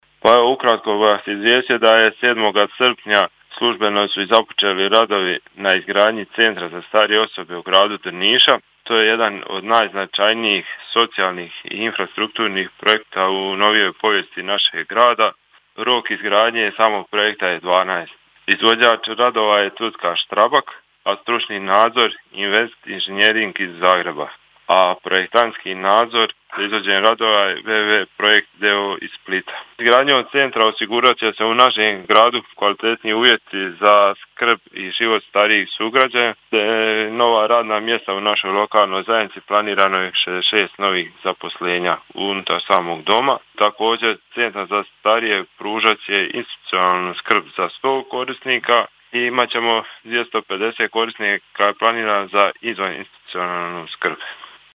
Gradonačelnik Drniša Tomislav Dželalija: